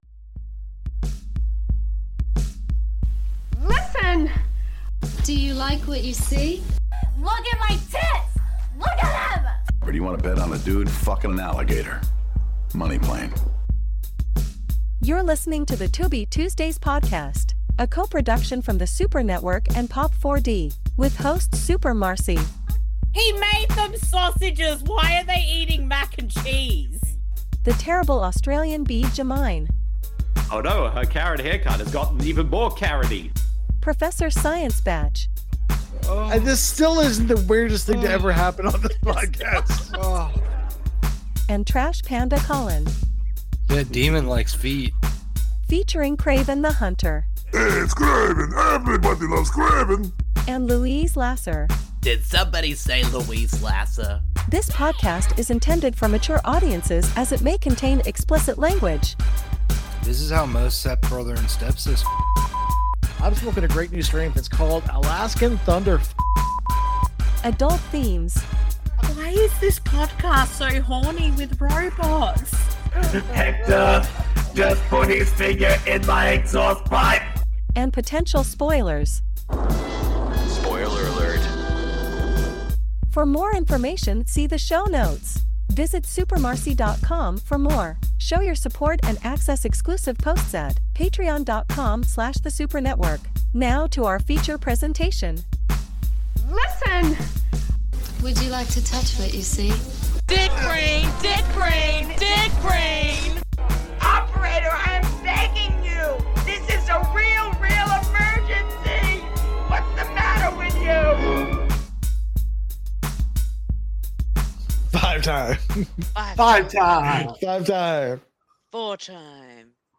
This podcast series is focused on discovering and doing commentaries/watch a longs for films found on the free streaming service Tubi, at TubiTV
Because we have watched the films on Tubi, it is a free service and there are ads, however we will give a warning when it comes up, so you can pause the film and provide time stamps to keep in sync.